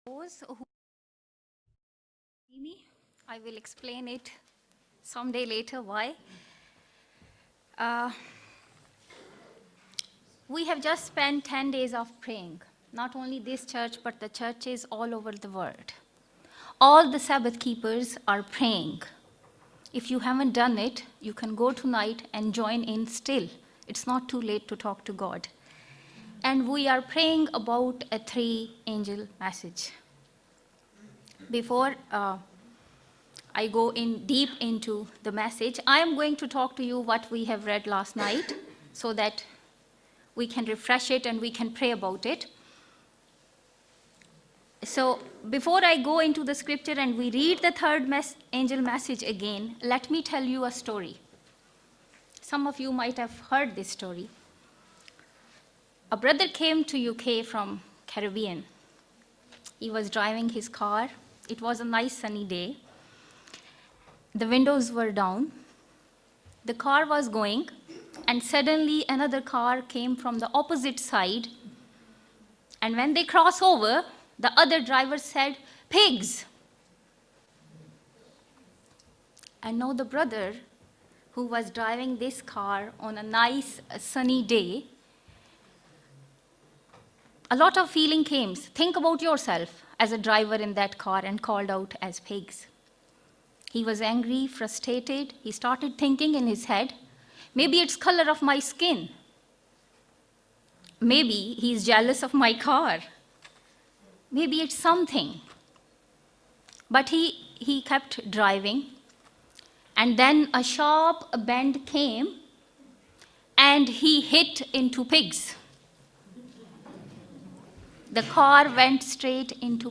on 2024-02-14 - Sabbath Sermons